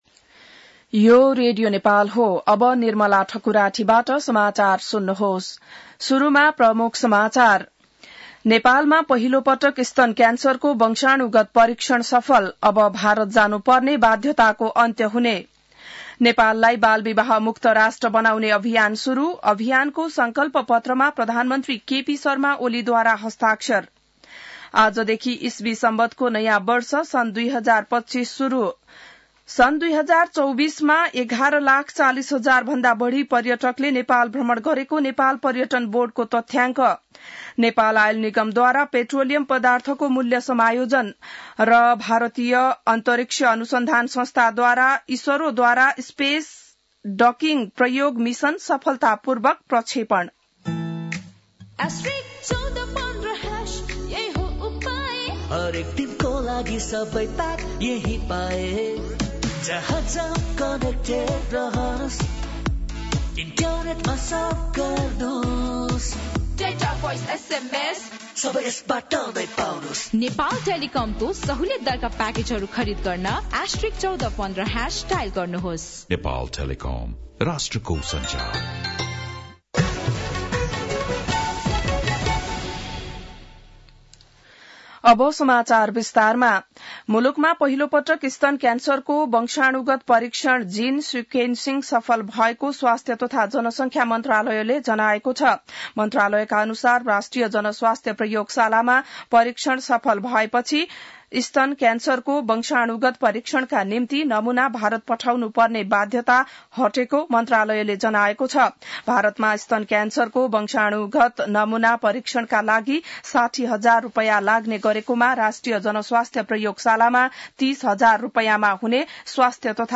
An online outlet of Nepal's national radio broadcaster
बिहान ७ बजेको नेपाली समाचार : १८ पुष , २०८१